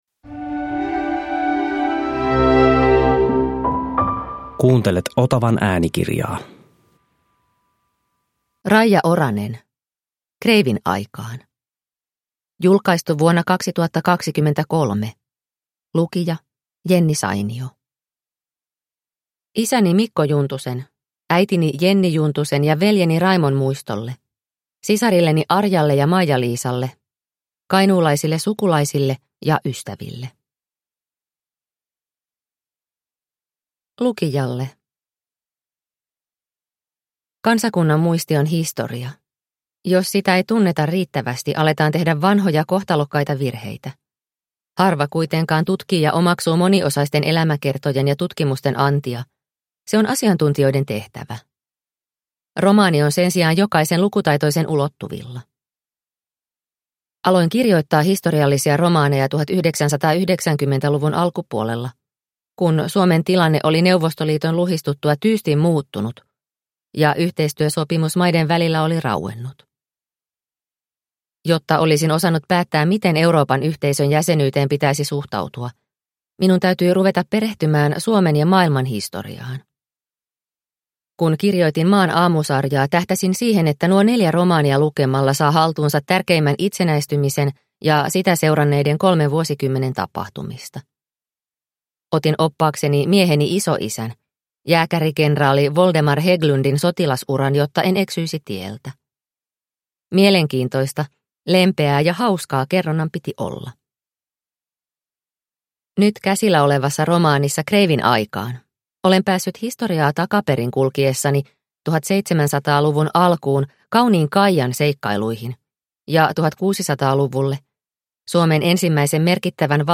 Kreivin aikaan – Ljudbok – Laddas ner